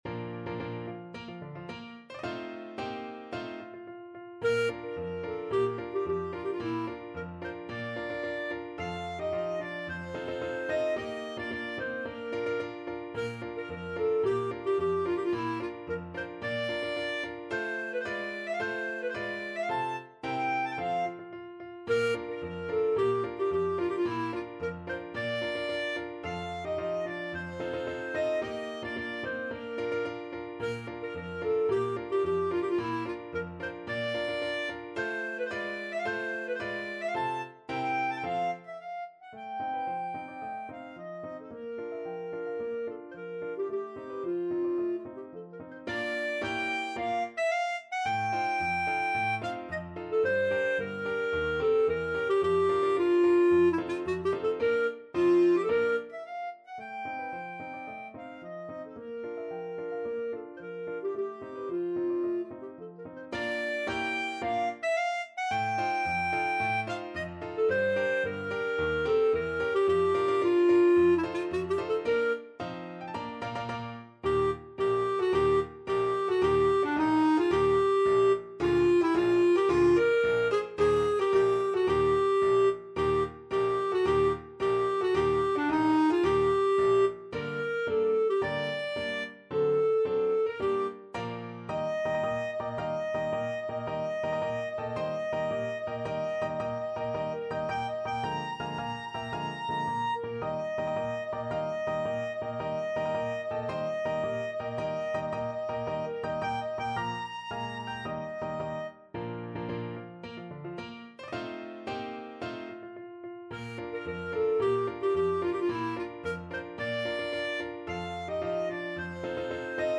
2/2 (View more 2/2 Music)
March =c.110
Classical (View more Classical Clarinet Music)